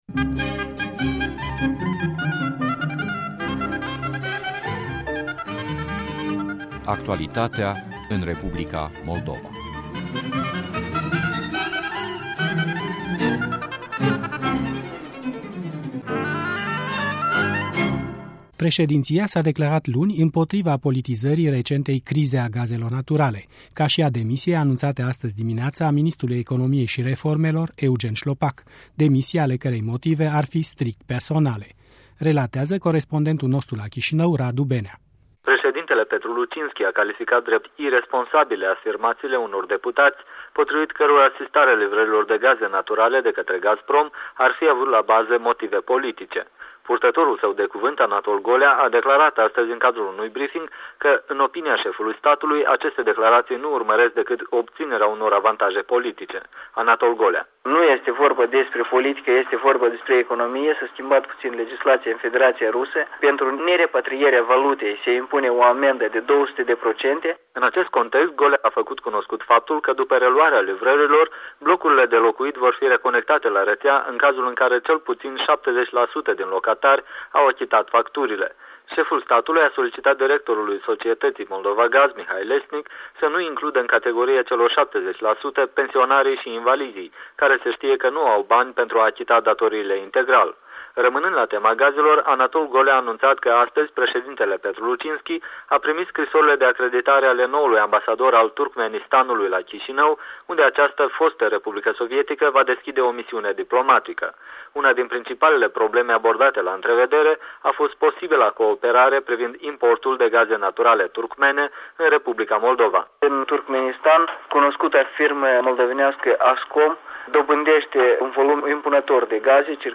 Briefing de presă al președinției Moldovei